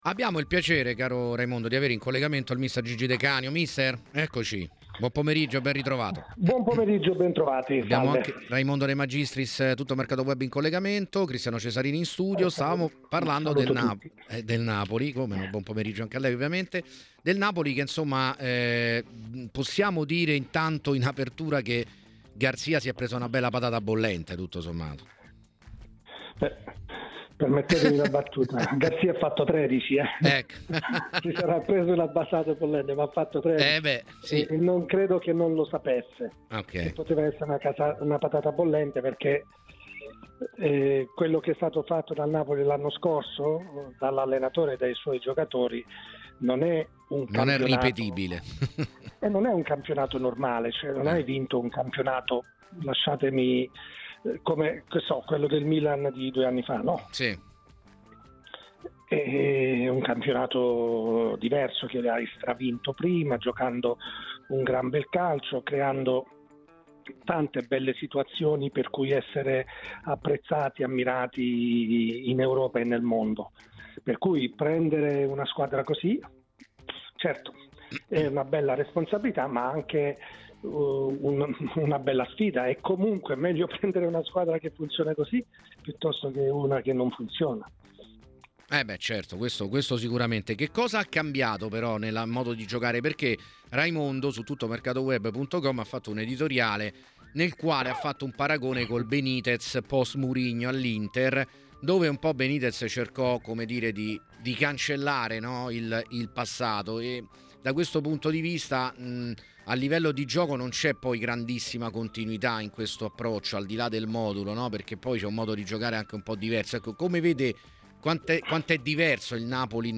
Durante la trasmissione "Piazza Affari", su TMW Radio, è intervenuto Luigi De Canio, allenatore ed ex calciatore